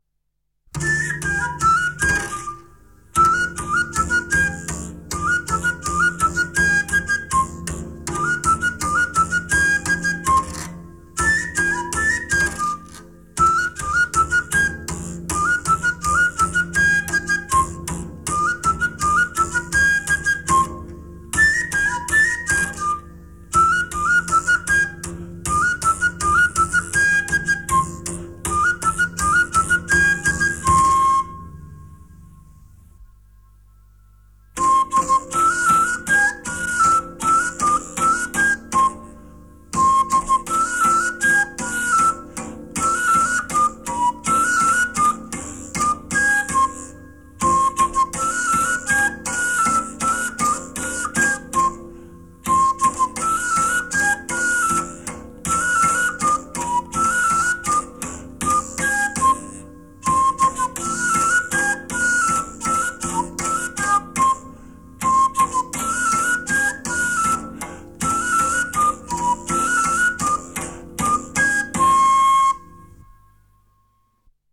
El chiflo és una flauta de fusta amb tres forats, amb un paravent metàl·lic.
Es toca amb la mà esquerra, i es fa colpejant les cordes amb una baqueta llarga i prima, d’uns 40 cm.
Chiflo y salterio
06-chiflo-y-salterio-aragc3b3.m4a